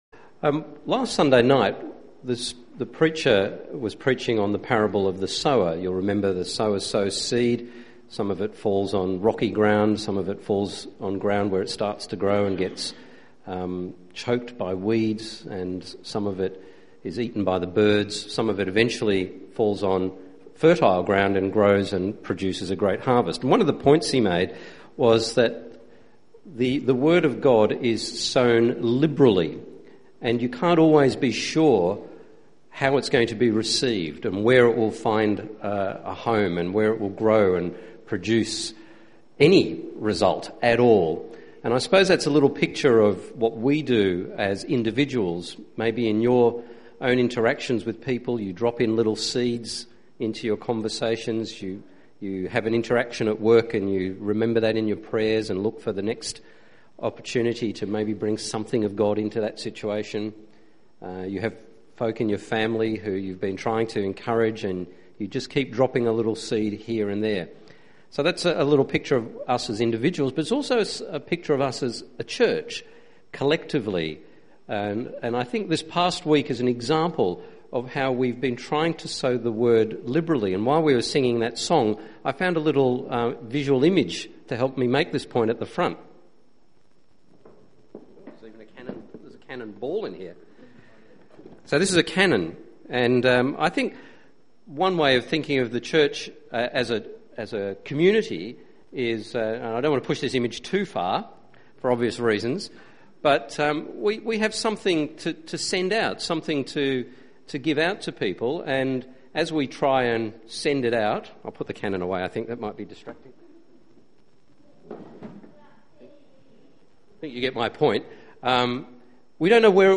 ' as part of the series 'Standalone Sermon'.